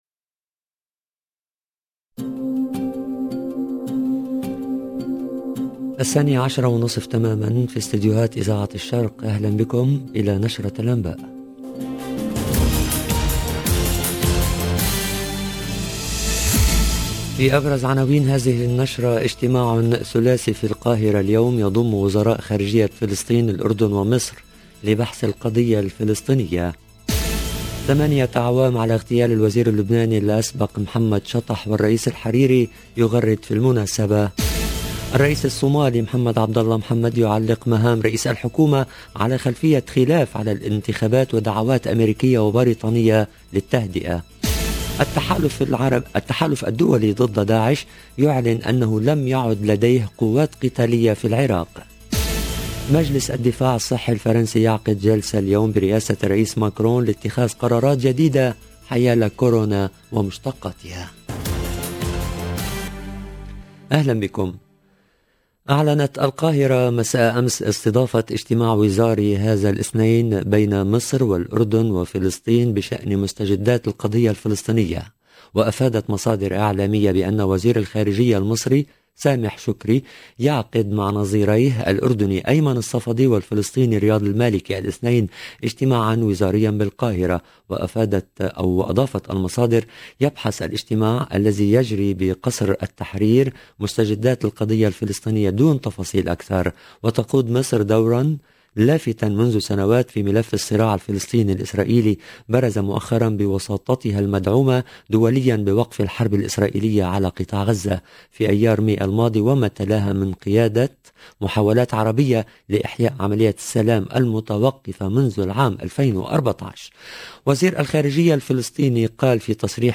LE JOURNAL DE MIDI 30 EN LANGUE ARABE